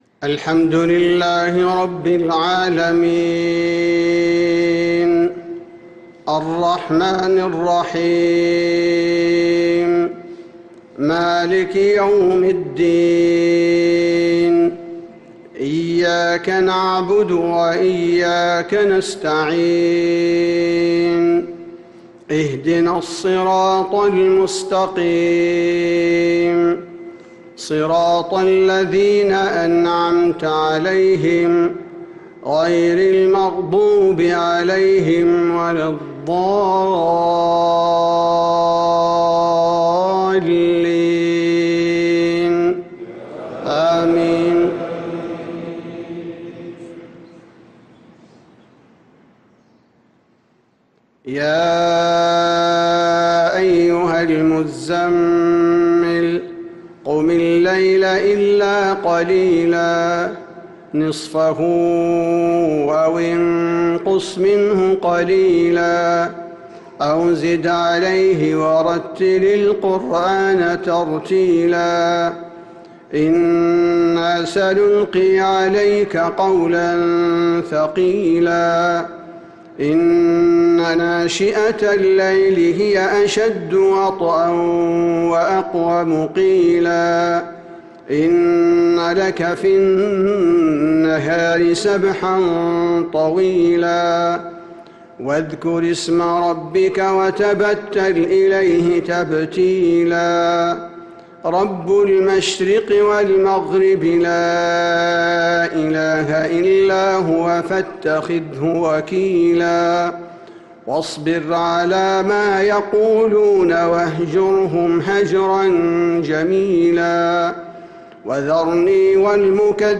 صلاة الفجر للقارئ عبدالباري الثبيتي 11 ذو القعدة 1445 هـ
تِلَاوَات الْحَرَمَيْن .